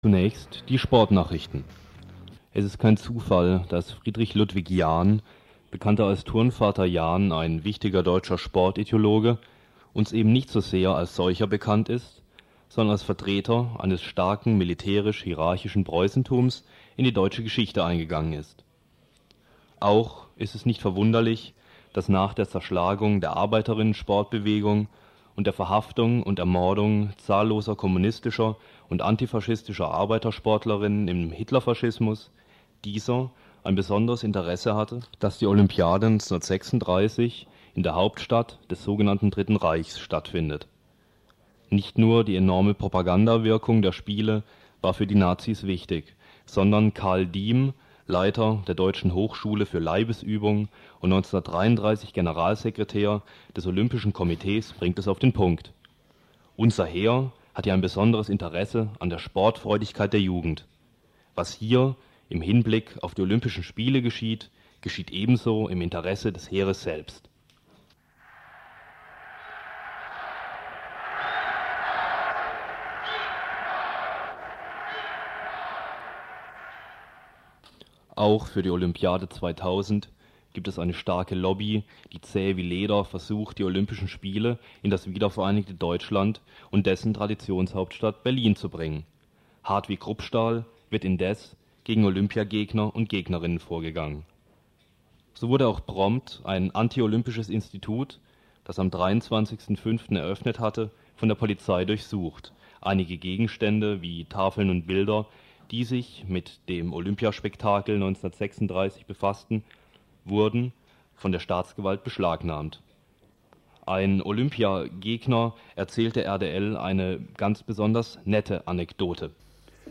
Jüngster Stand der Ereignisse. Interview mit Olympia-Gegner.